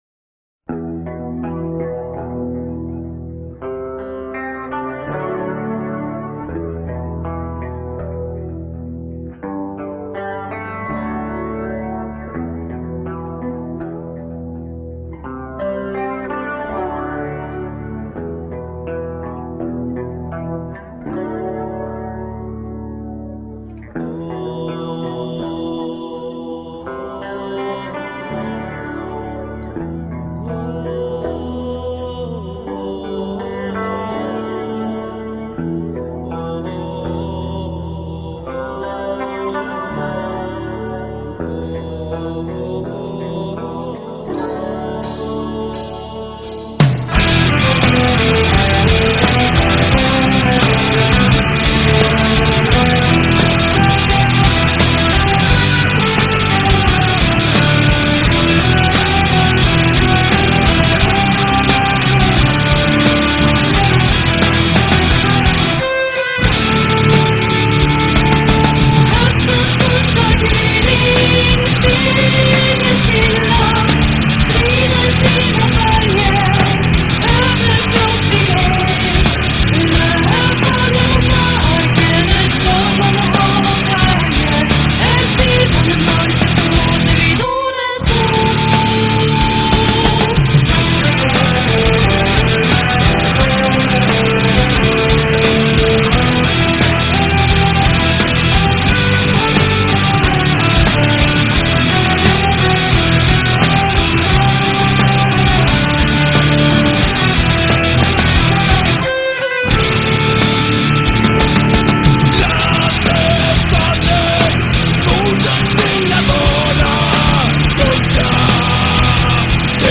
Viking_Folk_Pagan_Metal_Volume_I_50k.mp3